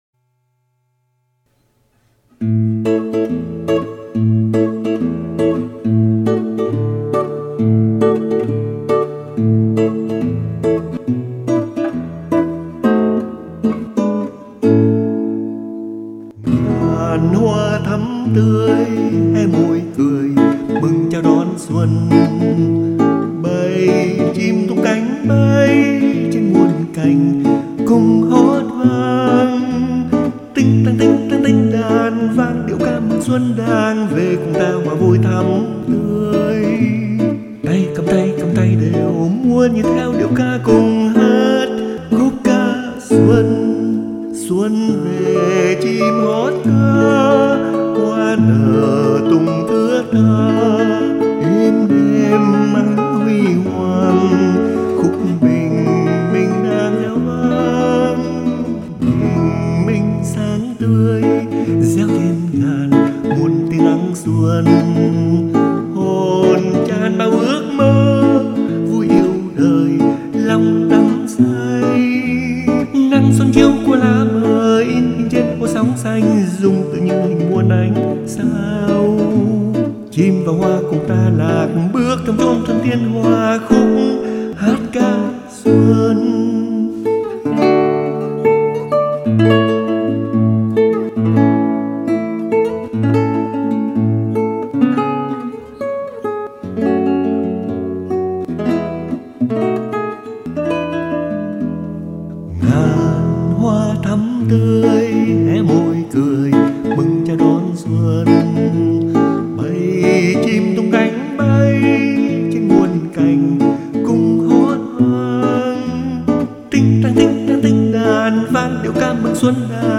đàn và hát
với tiết tấu vui tươi